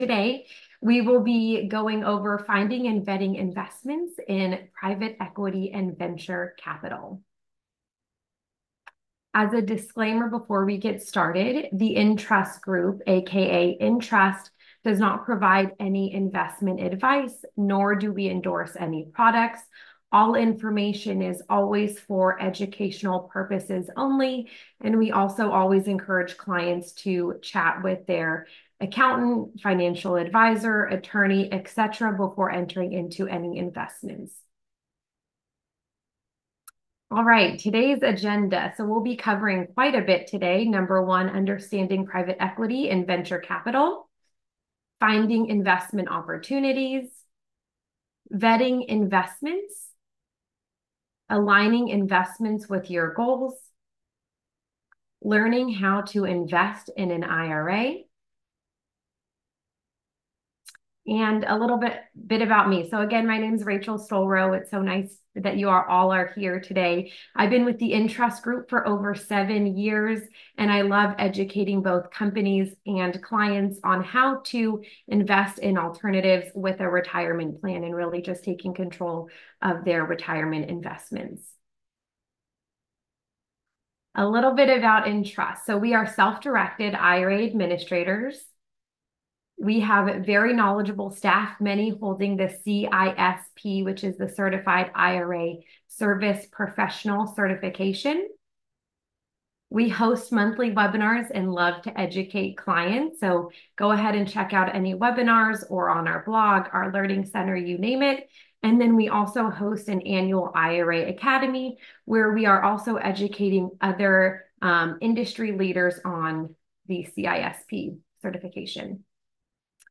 In this webinar replay, you will see what experts across a variety of alternatives think about the current market forces, how to find & vet investments and how to stay safe in a world full of scammers attacking your defenses.